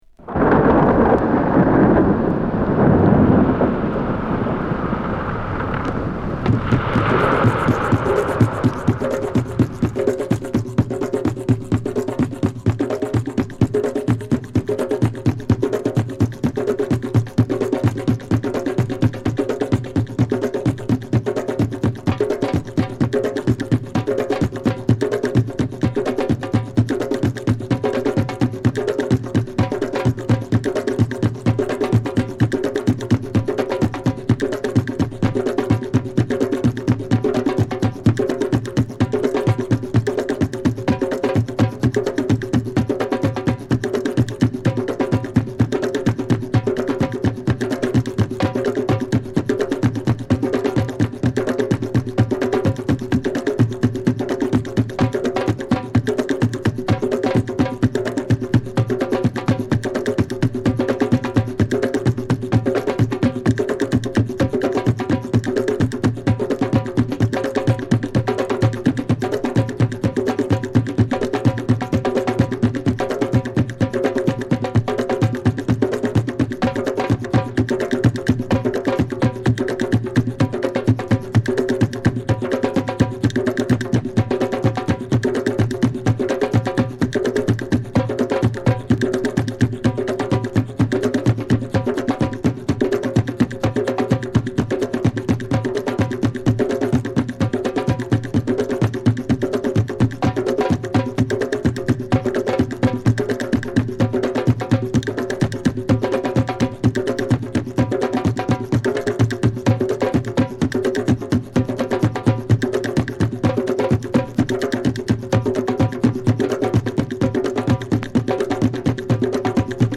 フレンチ・アフロ／ディスコ／ファンクバンド